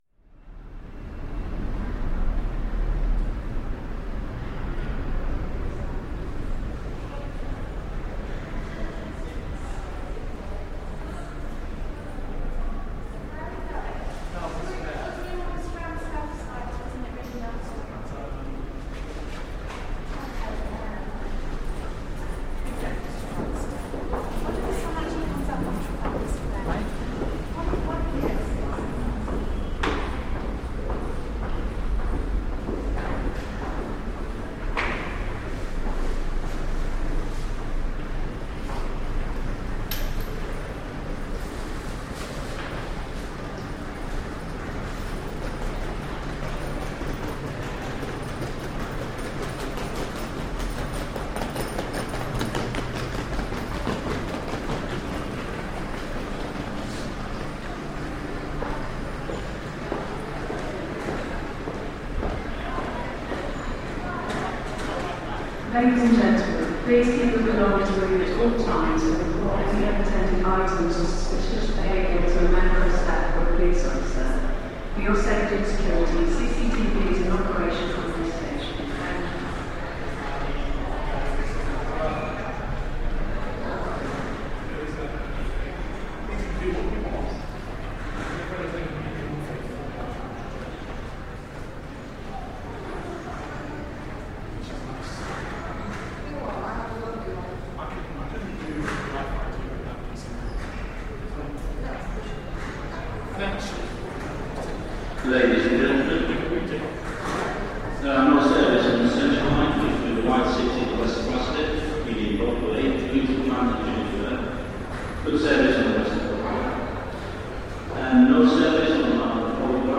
Charing Cross station subways
Field recording from the London Underground by London Sound Survey.